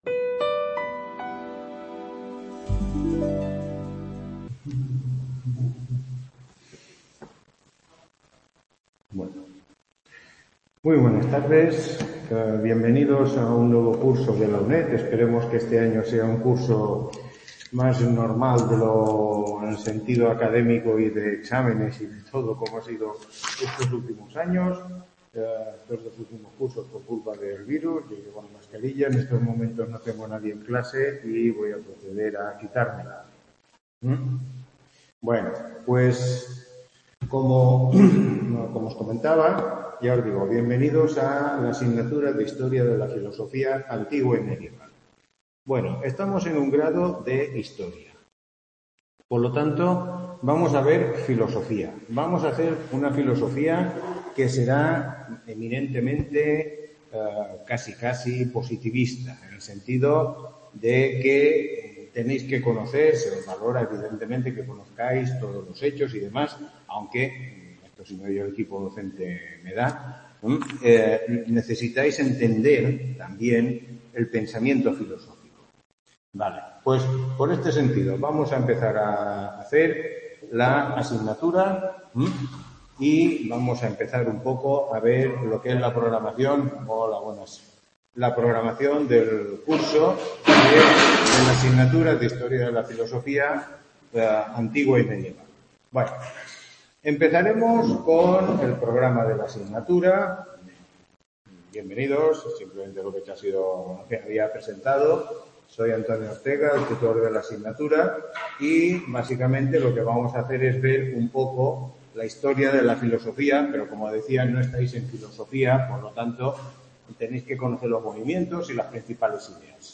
Tutoría 1